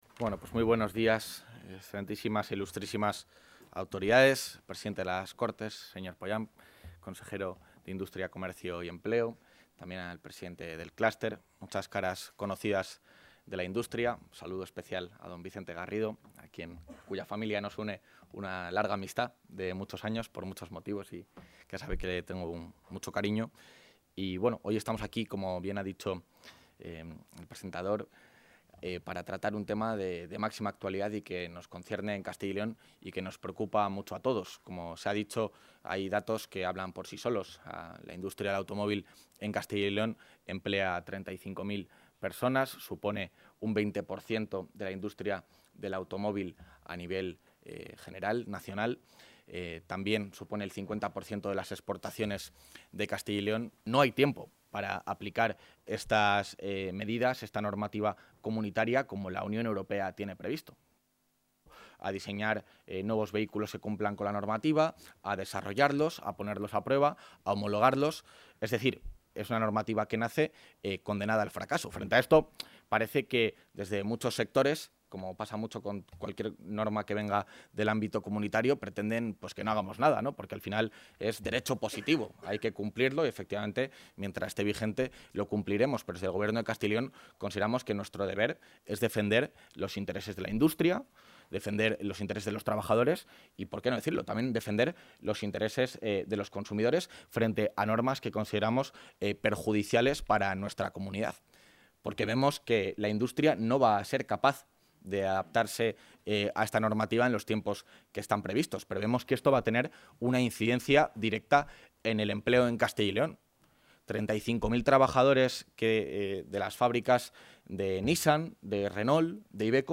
Intervención del vicepresidente de la Junta.
El vicepresidente de la Junta de Castilla y León, Juan García-Gallardo, ha participado esta mañana en la jornada ‘Euro 7 y Combustión Neutra 2035’, un encuentro organizado por Castilla y León Económica en el Edificio de Soluciones Empresariales de Arroyo de la Encomienda para analizar las implicaciones, oportunidades y amenazas de esta norma para la industria de Castilla y León.